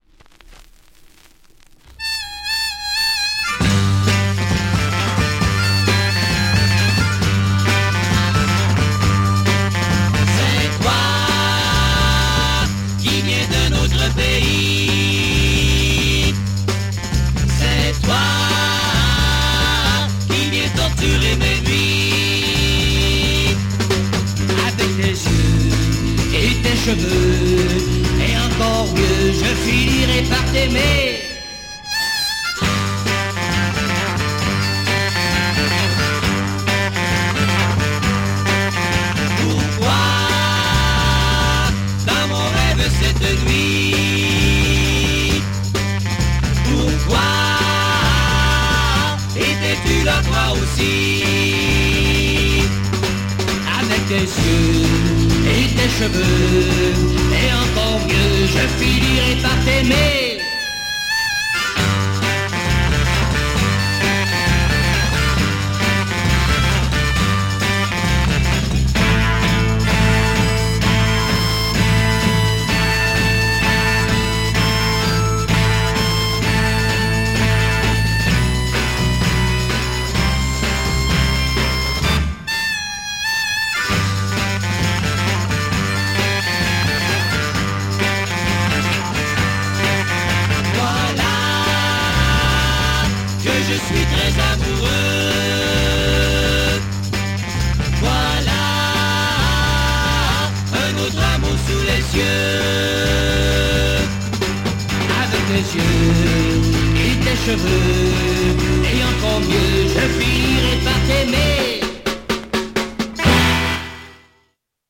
French Quebec Garare teen 7"
Brillant French Quebec garage single!!